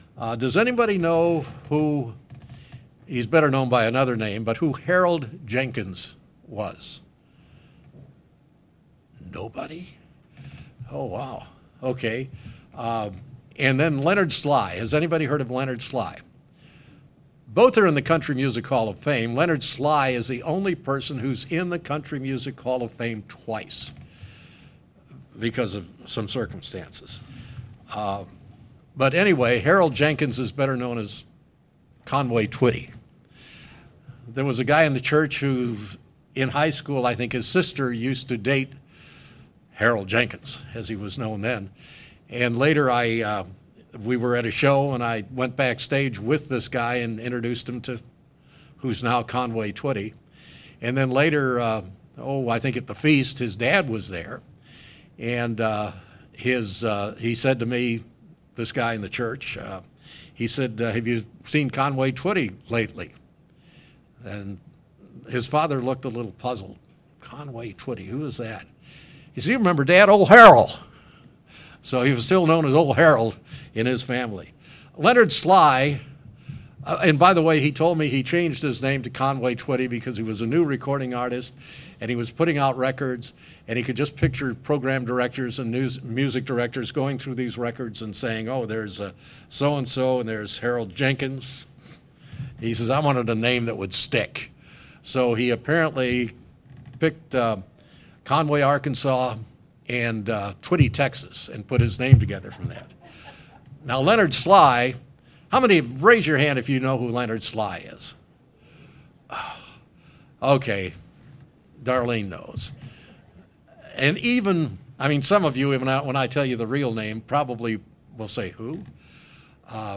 The name of God is to be respected and used appropriately. In this sermon, the speaker looks through the scriptures concerning God's command to not take His name in vain and how we are to use God's name.
Given in Springfield, MO